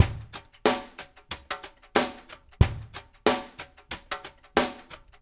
CVS DRUMS -L.wav